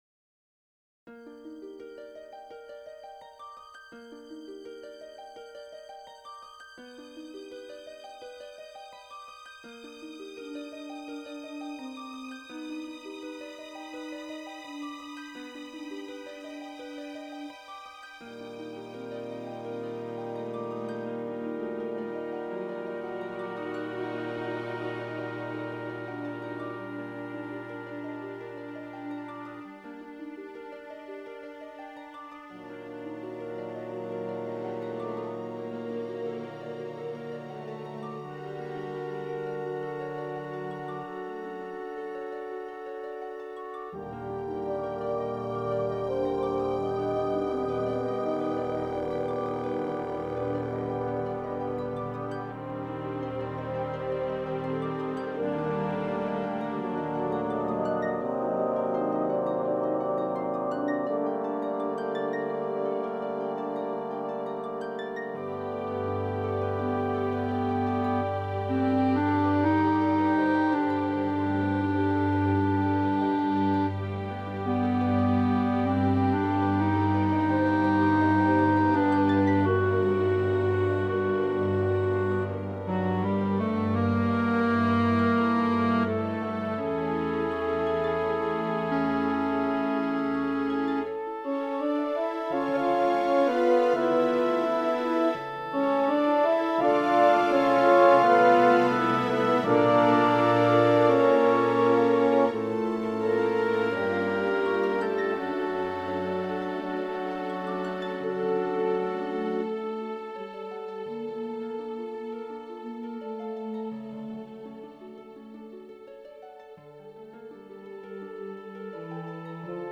Here is a MIDI rendering of the orchestral score for the Missa Pax.
In real life tempos may vary depending on the mood, also whenever tempos are free (for example, the ending) these renderings are not accurate. Also, the percussion is not always represented at all and sometimes throws in odd sounds here and there.
AgnusDei_midi.mp3